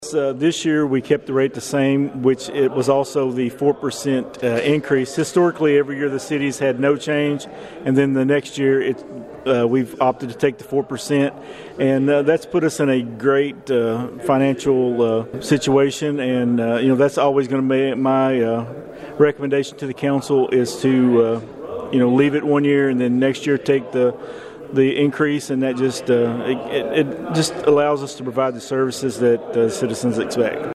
The Princeton City Council met in regular session at 5:00 pm on Monday afternoon.
Mayor Thomas presented the 2025 Property Tax Rate Ordinance for the first reading. Thomas explained the rate proposal.